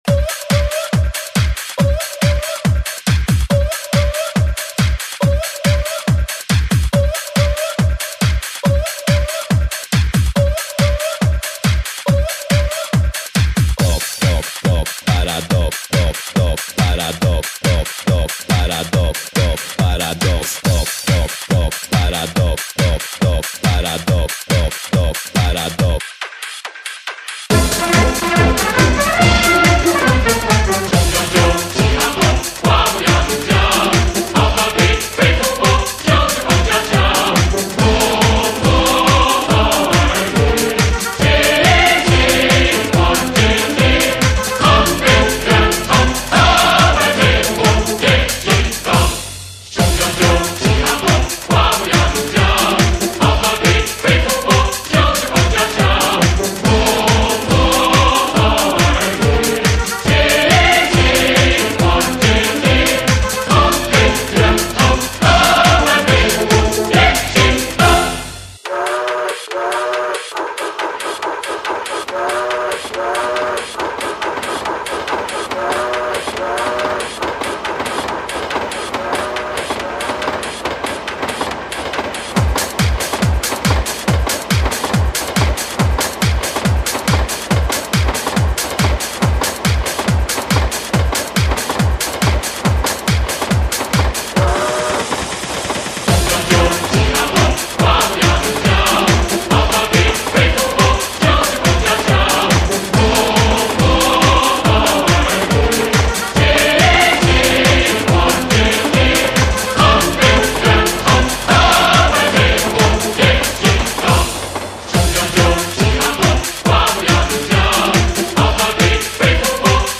最新瑞典DJ MIXING
中、瑞两国音乐人强强合作
史无前例革命歌曲DISCO大联唱